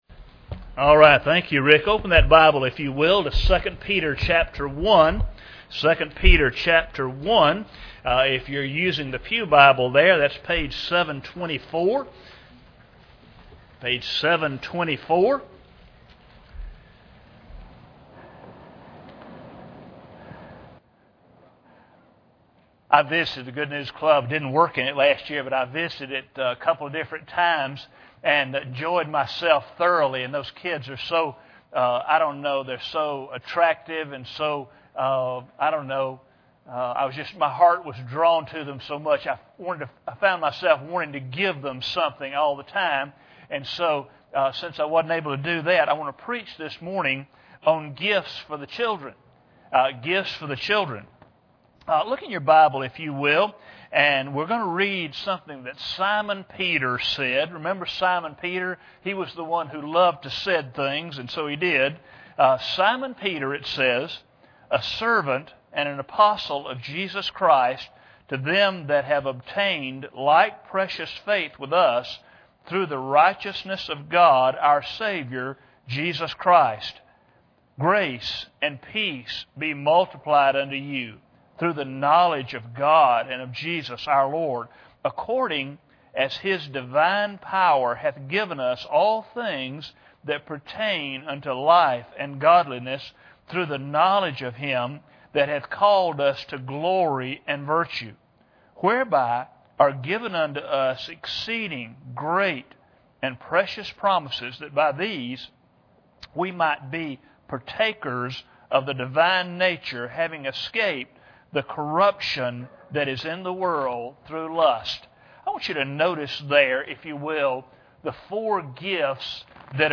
2 Peter 1:1-4 Service Type: Sunday Morning Bible Text